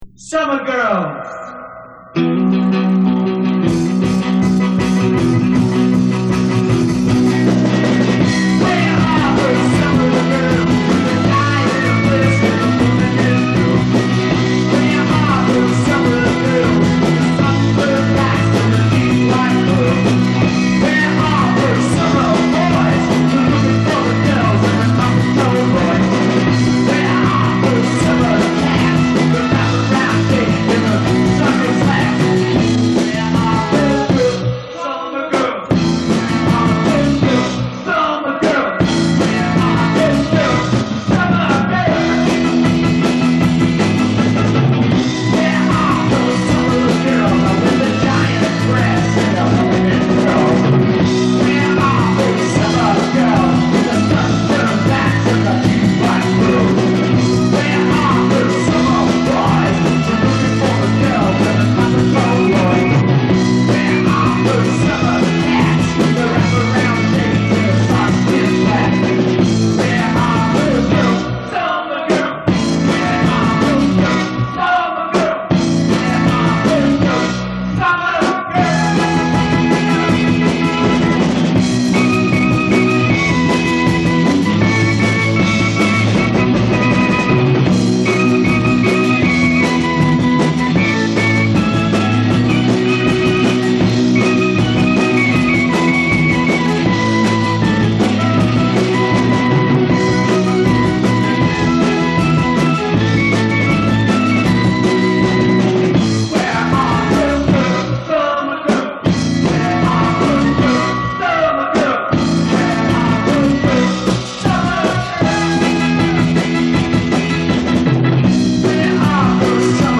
Live in Rehearsal